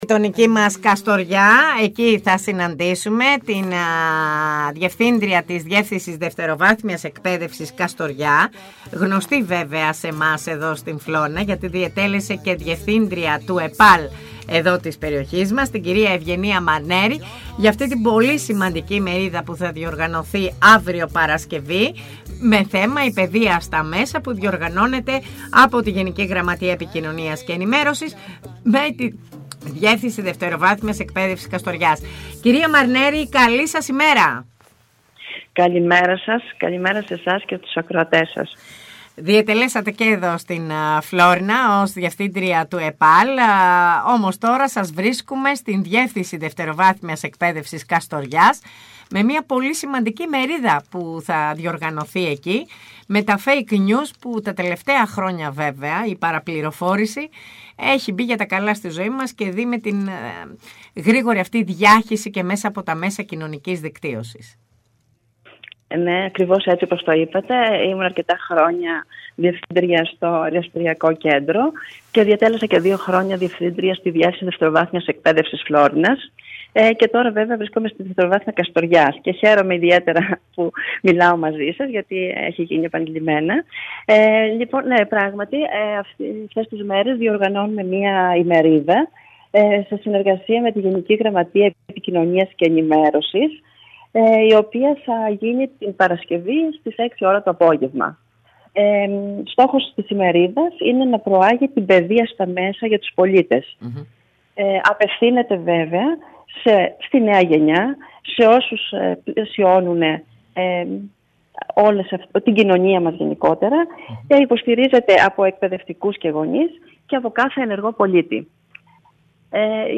Η Ευγενία Μαρνέρη και ο Δημήτρης Γαλαμάτης καλεσμένοι στην ΕΡΤ Φλώρινας | 16.05.2025